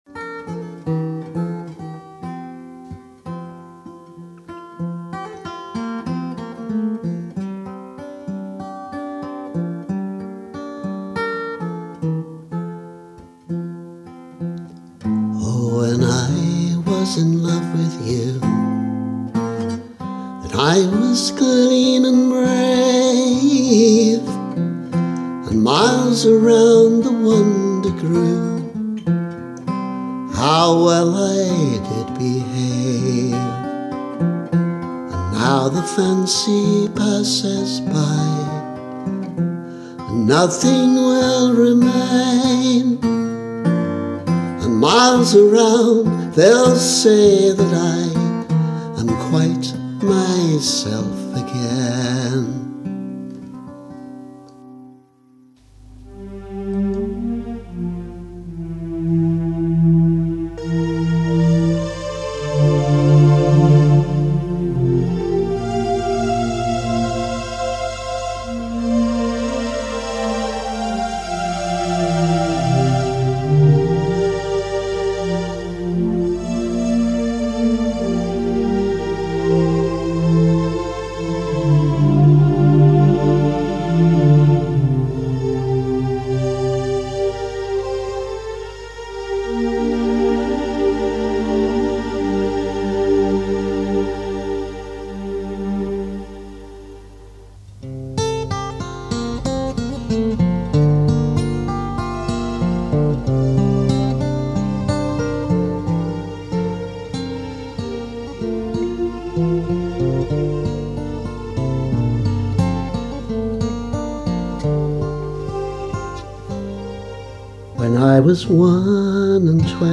There are already demo recordings on this site of the two individual settings, which use the same tune, but this is a sketch for a more ambitious orchestral arrangement that combines the two. Sadly, I didn’t have an orchestra handy, so the strings here come courtesy of a Yamaha keyboard. The guitar part is actually a guitar, though.